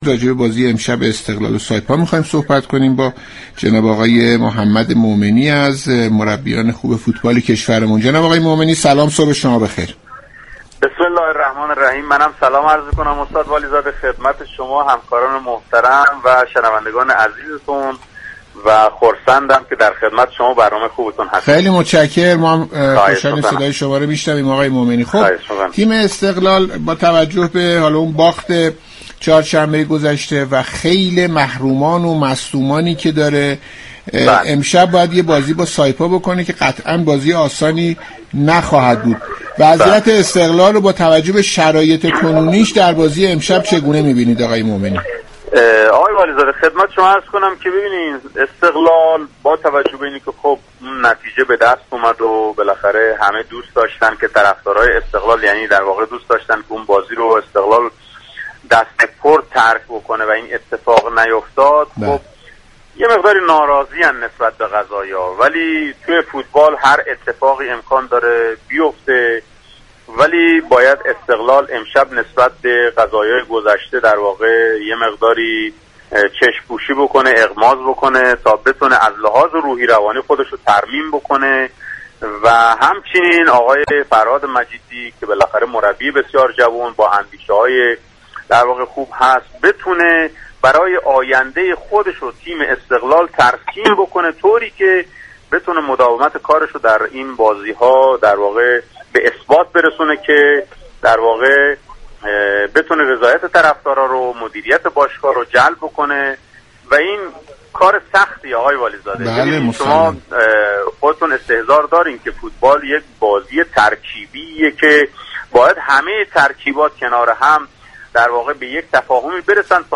شما می توانید از طریق فایل صوتی پیوست شنونده این گفتگو باشید.